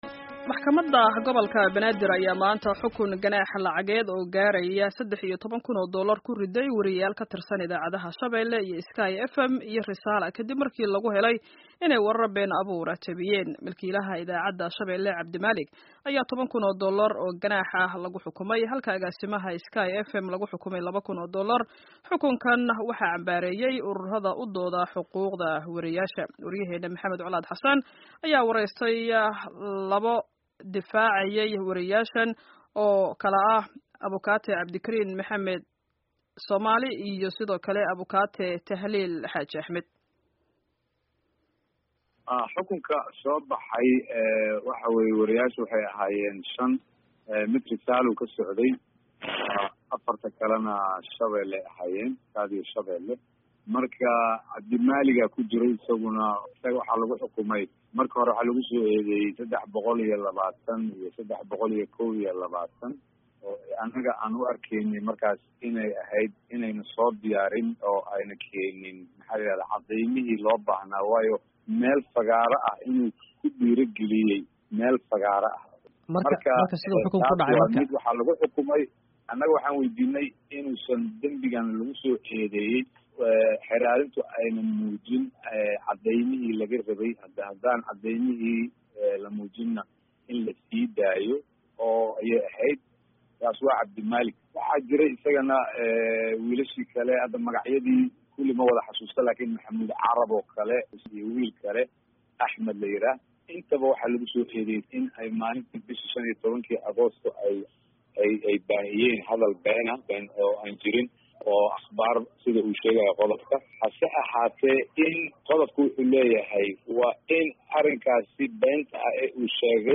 Dhageyso wareysiyada xukunka wariyeyaasha